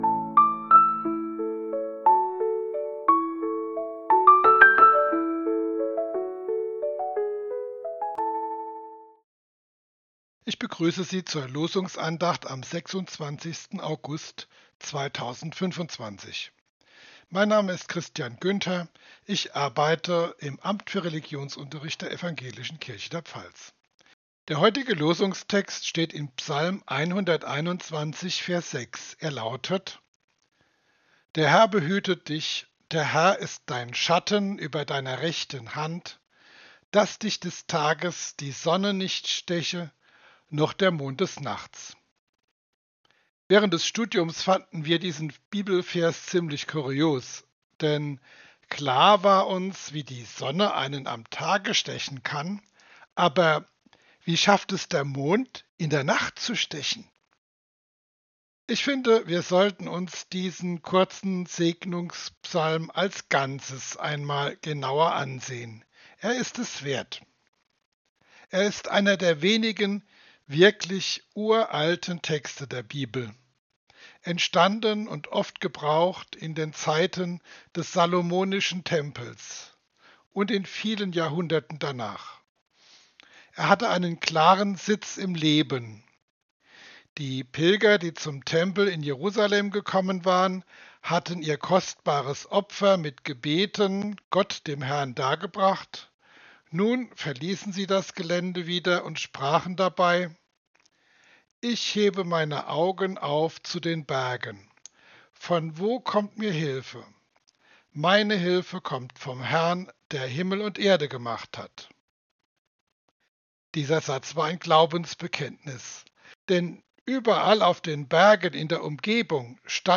Losungsandacht für Dienstag, 26.08.2025